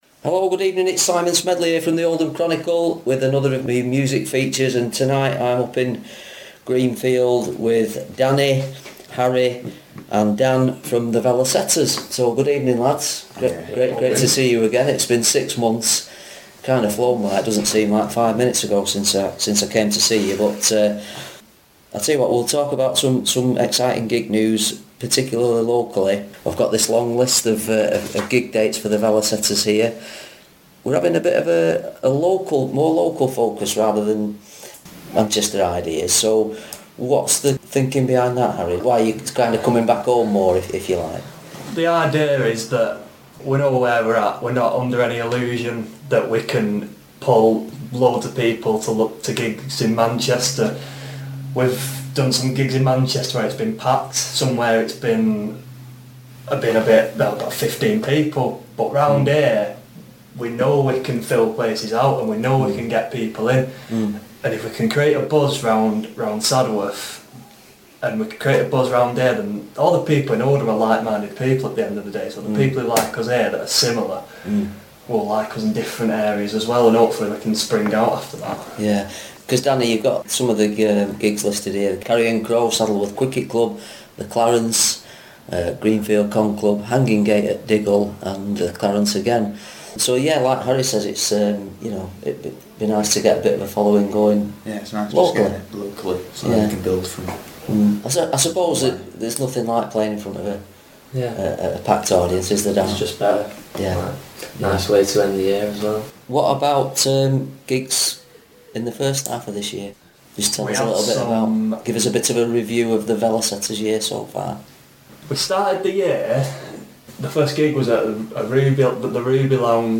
The Velocetas in conversation